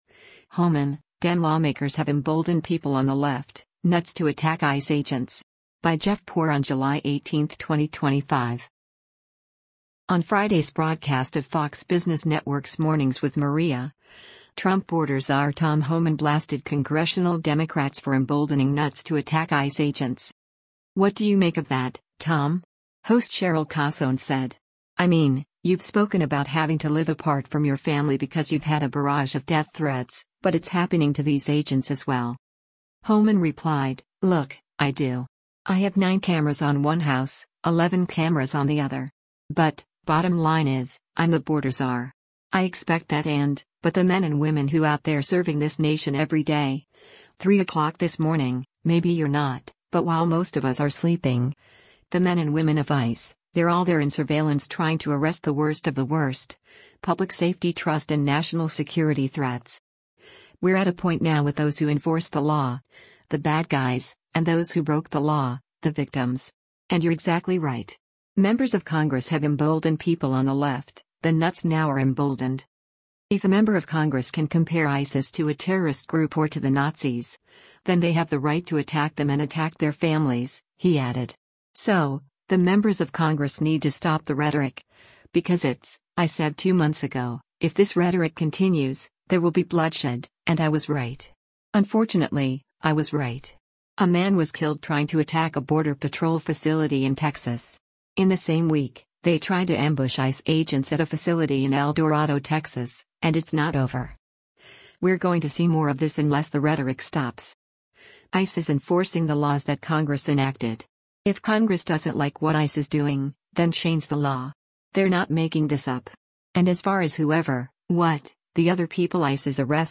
On Friday’s broadcast of Fox Business Network’s “Mornings with Maria,” Trump border czar Tom Homan blasted congressional Democrats for emboldening “nuts” to attack ICE agents.